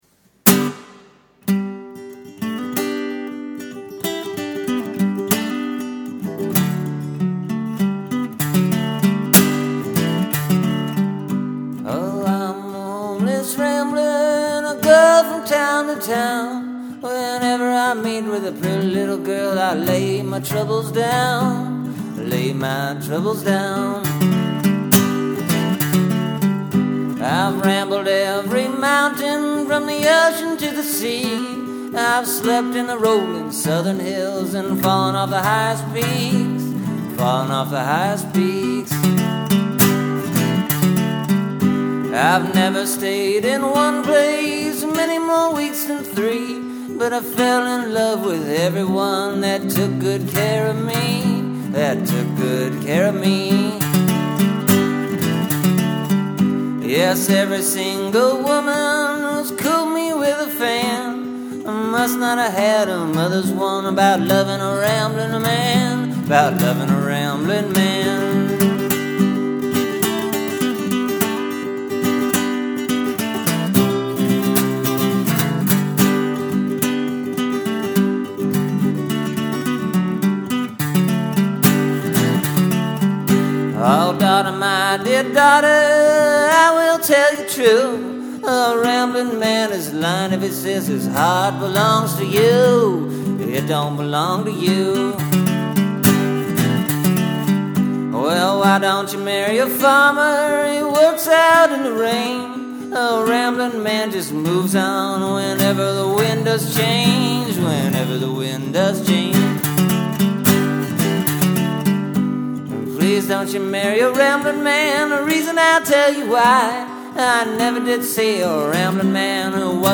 This recording I did in my cold cold cold kitchen.
It’s folk music.
Hear those pigeon calls?